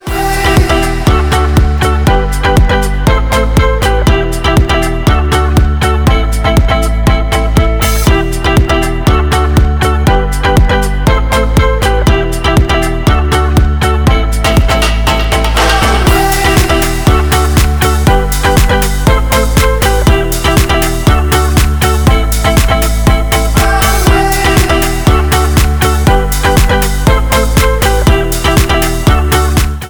• Dance